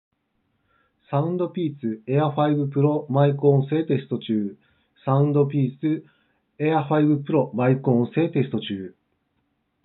マイク性能は良くも悪くもない中間レベル